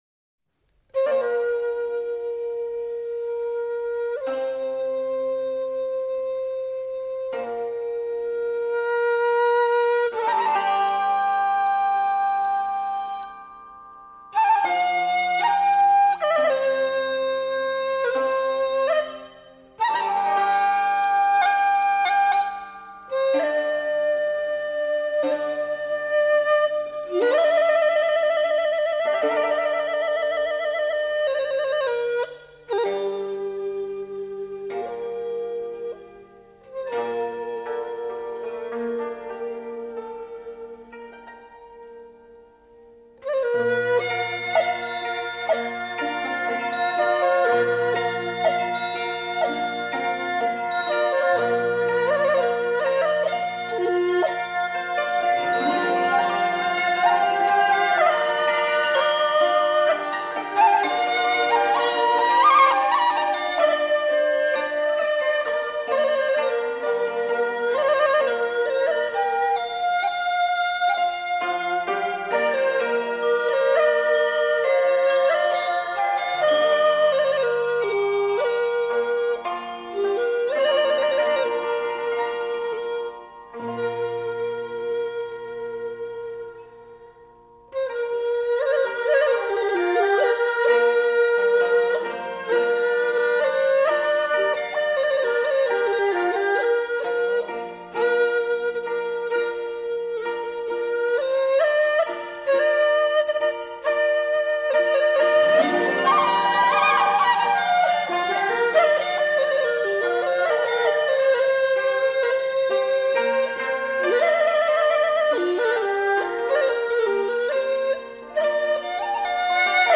古曲